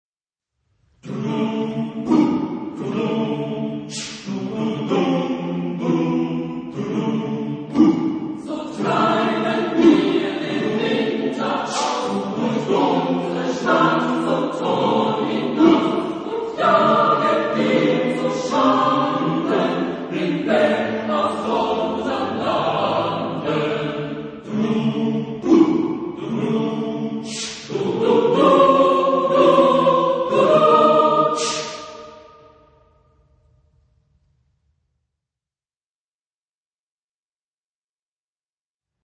Género/Estilo/Forma: Folklore ; Canción ; Profano
Tipo de formación coral: SSATB  (5 voces Coro mixto )
Tonalidad : dorico